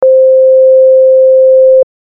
FM音源は基本的にサイン波を使うので、とりあえずAlpha(SSW付属)でサイン波を作り(
alpha_sine.mp3